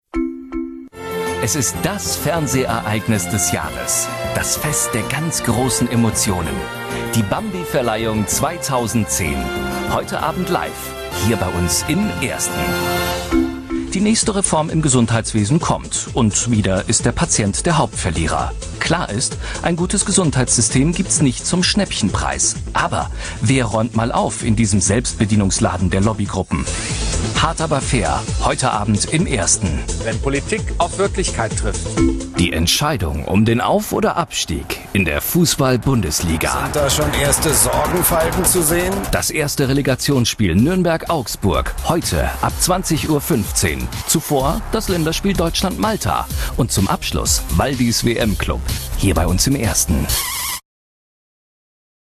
Sehr vielseitige und warme Stimme.
Kein Dialekt
Sprechprobe: Werbung (Muttersprache):
german voice over artist